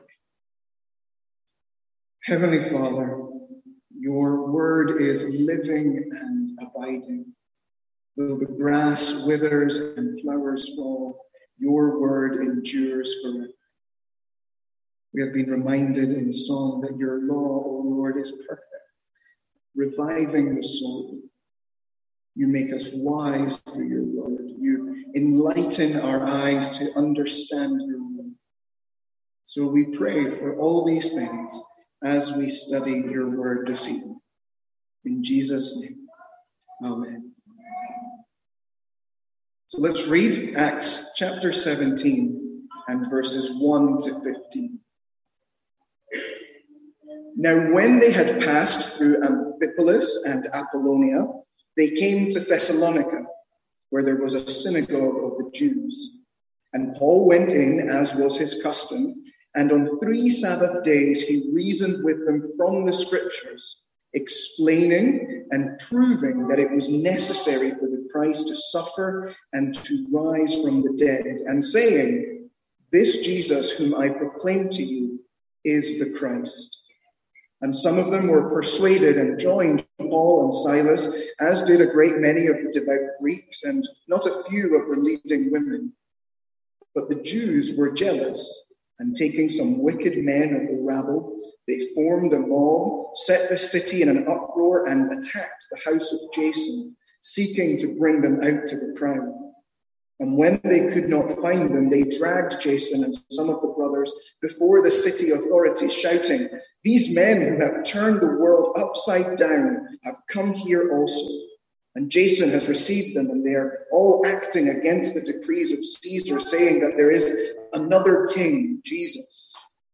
Sermons | St Andrews Free Church
From our evening series in Acts.